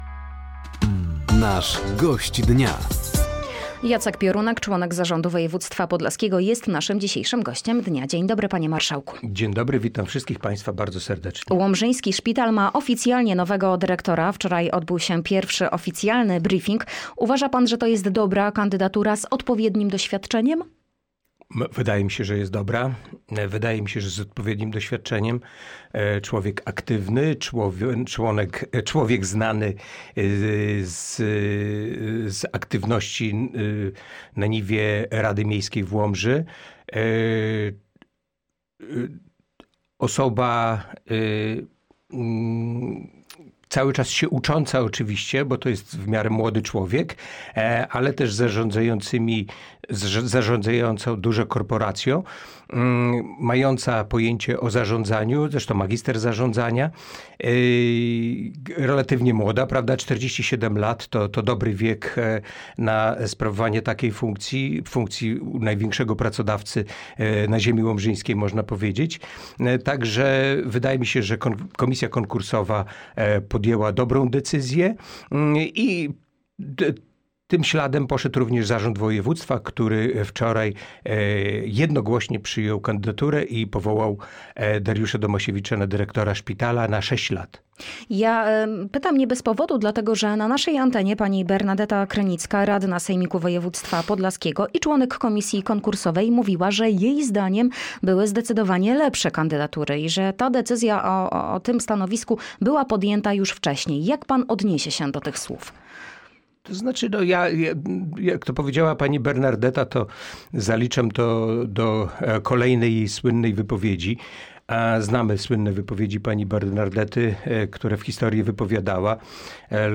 Nowa dyrekcja łomżyńskiego szpitala, sprzeciw władz Suwałk i Łomży wobec tworzenia Centrów Integracji Cudzoziemców oraz rozstrzygnięty przetarg na budowę linii kolejowej Łomża-Śniadowo i Śniadowo-Łapy – to główne tematy rozmowy z Gościem Dnia Radia Nadzieja, którym był Jacek Piorunek, członek zarządu woj. podlaskiego.